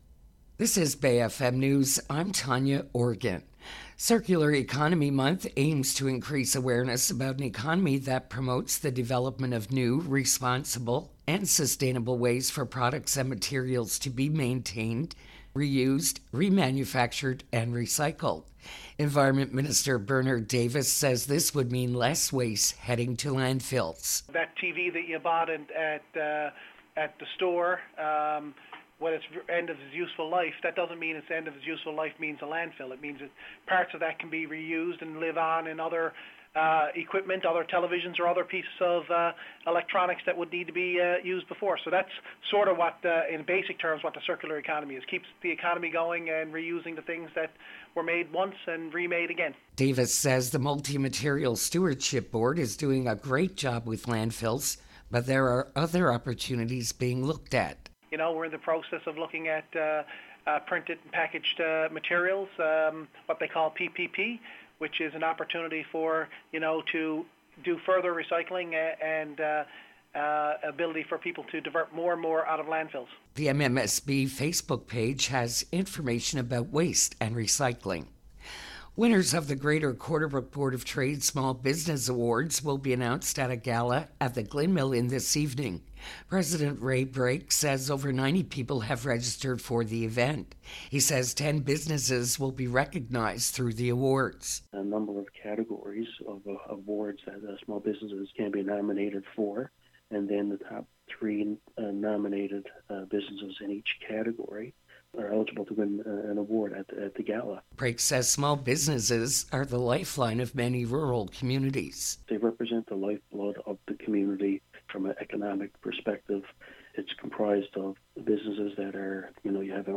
NL Environment Minister talks about circular economy during themed month